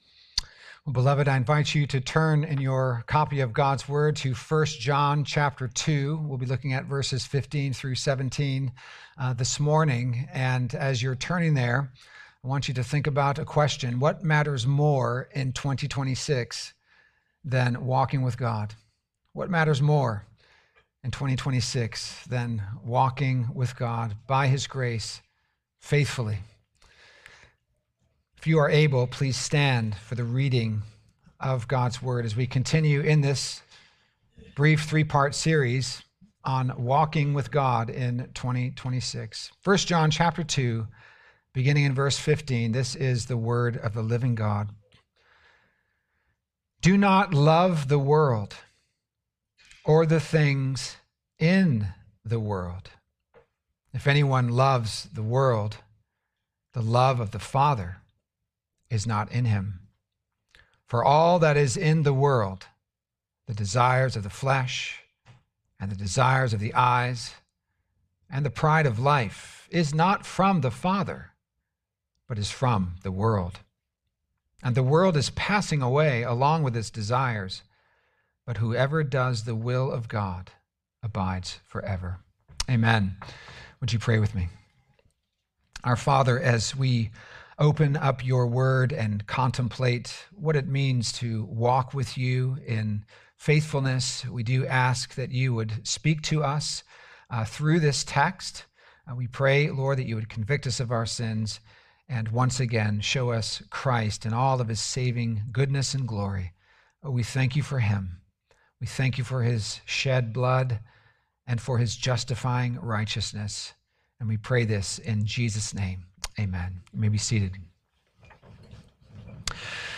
Sermons
Weekly sermons of Christ Church Presbyterian | Charleston, SC